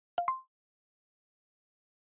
notification-new-message-1.wav